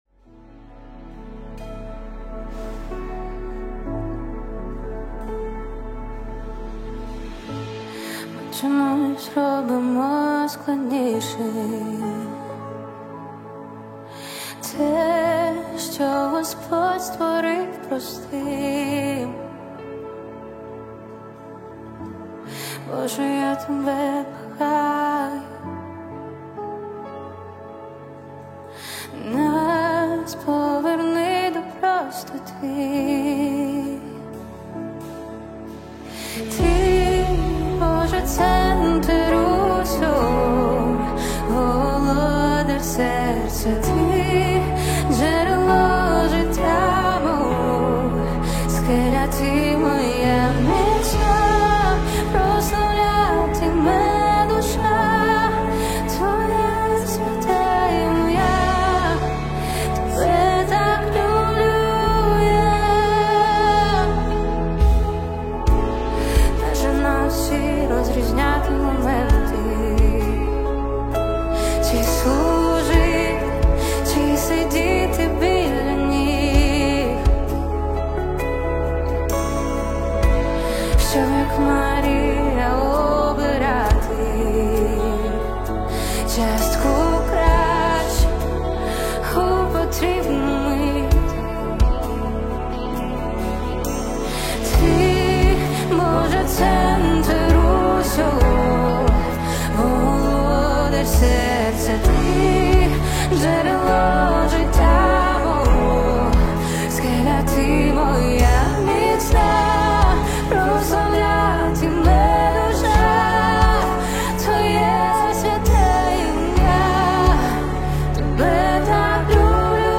4998 просмотров 4234 прослушивания 174 скачивания BPM: 132